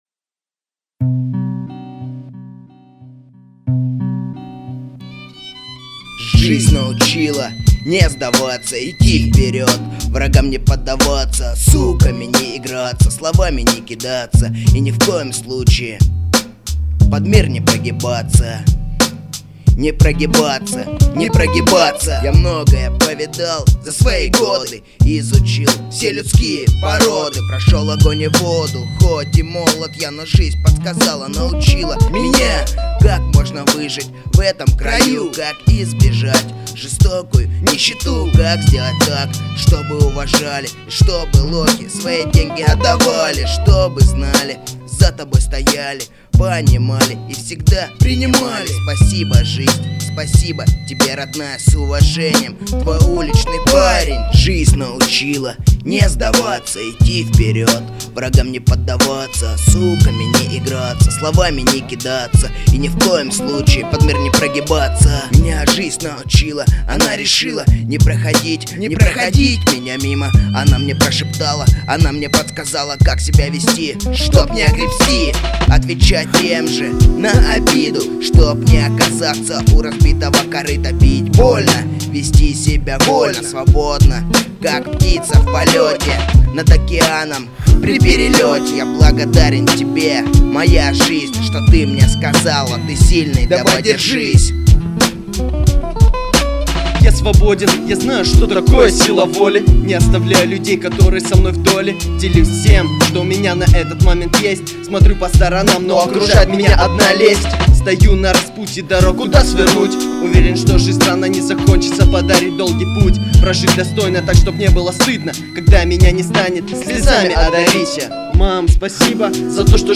mp3,2525k] Рэп